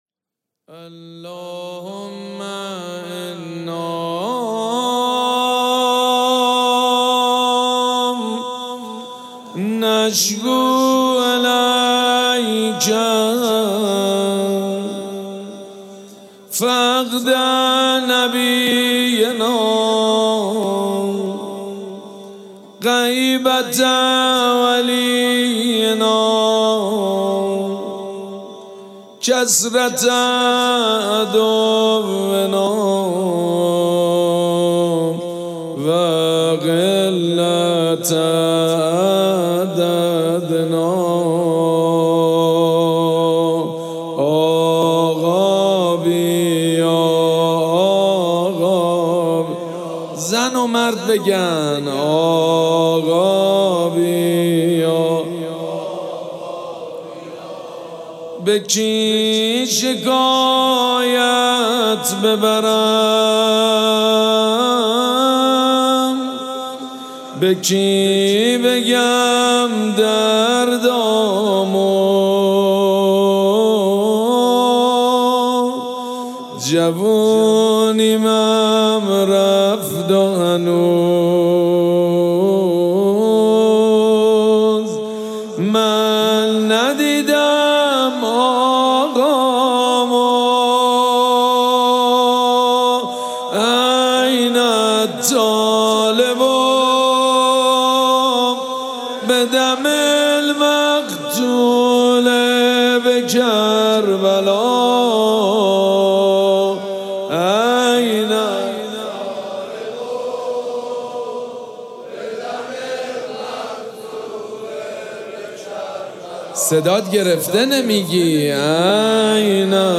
مراسم عزاداری شام غریبان محرم الحرام ۱۴۴۷
مناجات
حاج سید مجید بنی فاطمه